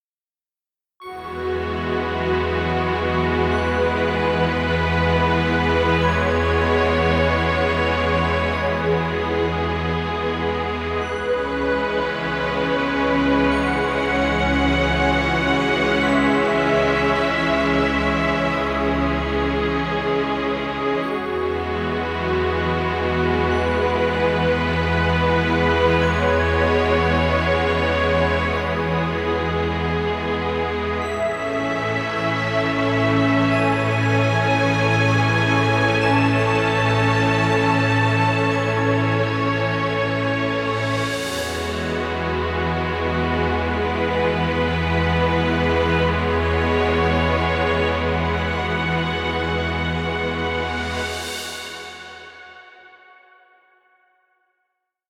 calm ambient track.